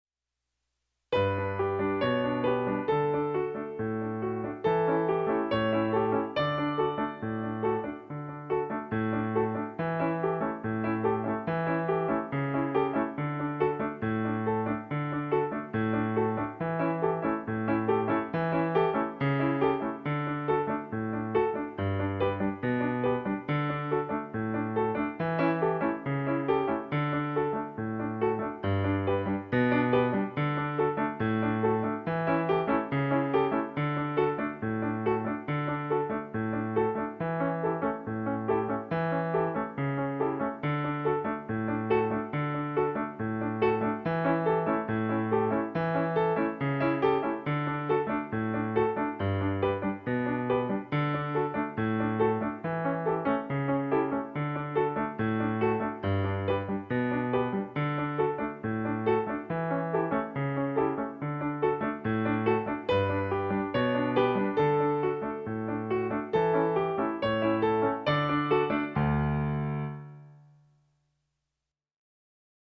Piano